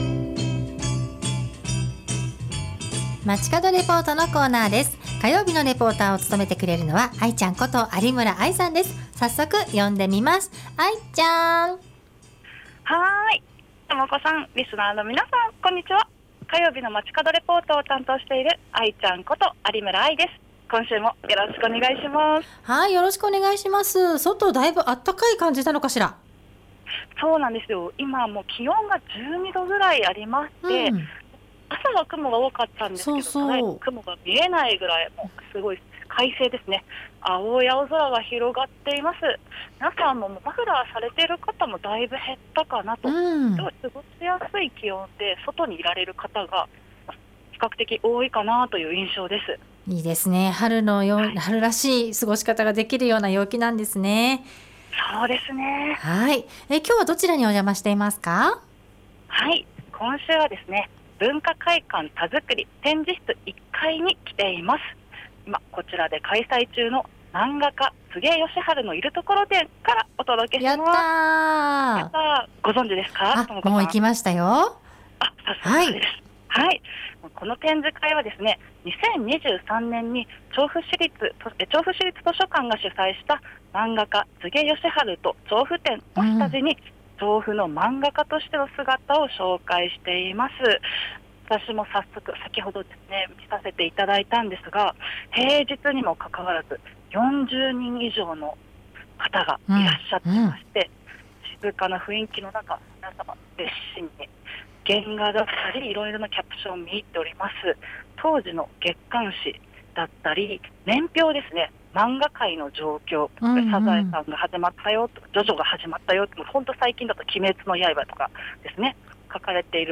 今週は文化会館たづくり1階展示室で開催中の 「マンガ家・つげ義春のいるところ展」からお届けしました！